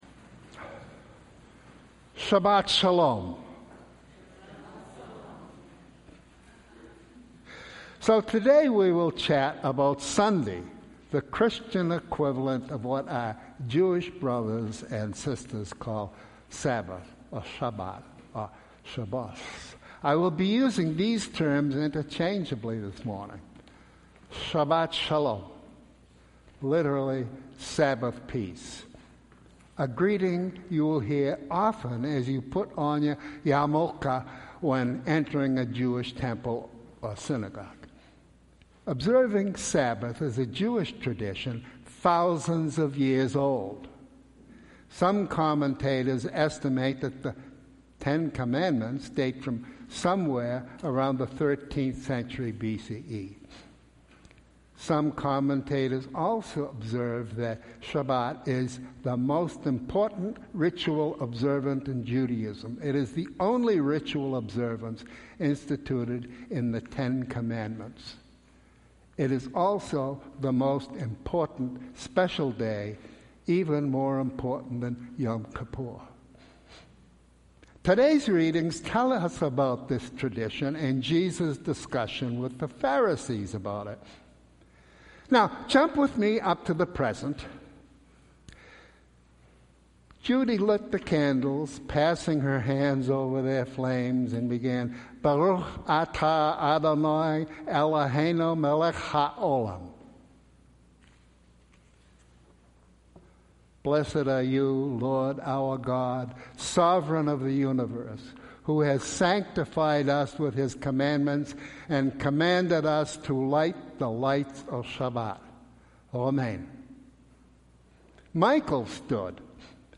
Sermon- June 3, 2018 – 2nd Sunday after Pentecost